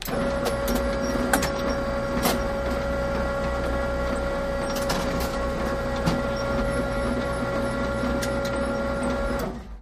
Photocopier Xerox 1012 Make Copy